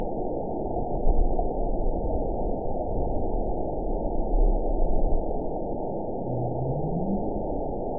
event 911662 date 03/06/22 time 06:28:43 GMT (3 years, 2 months ago) score 9.65 location TSS-AB04 detected by nrw target species NRW annotations +NRW Spectrogram: Frequency (kHz) vs. Time (s) audio not available .wav